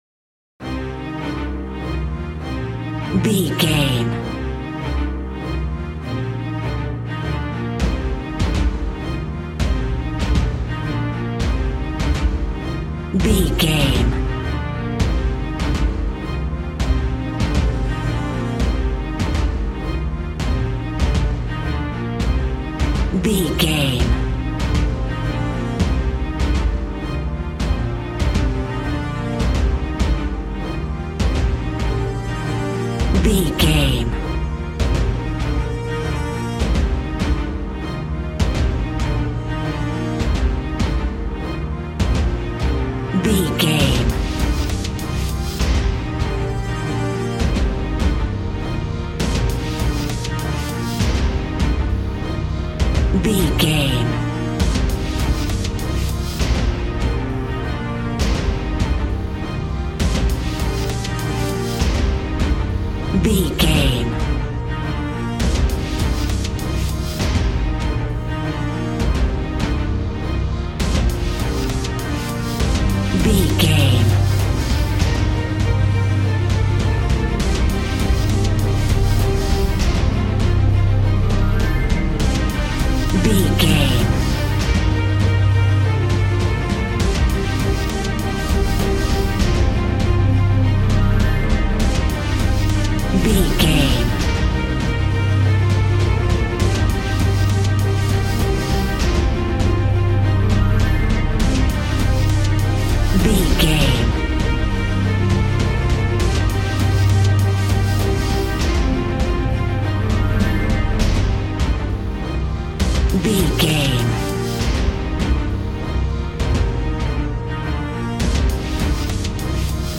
Epic / Action
Fast paced
In-crescendo
Aeolian/Minor
strings
brass
percussion
synthesiser